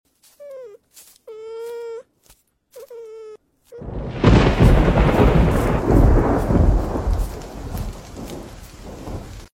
The little penguin is scared sound effects free download